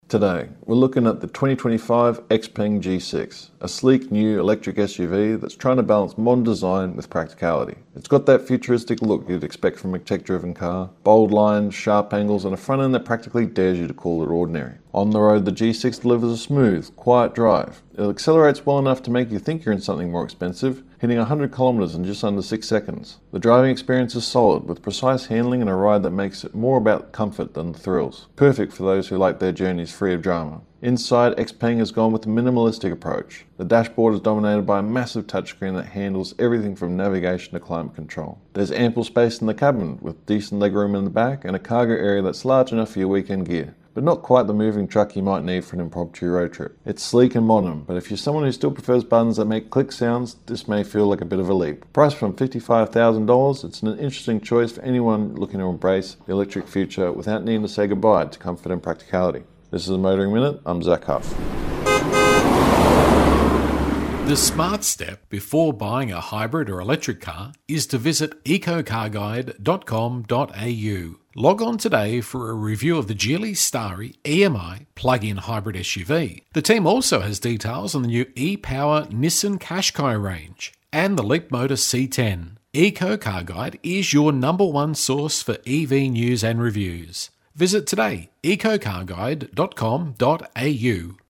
XPENG G6 EV SUV road test review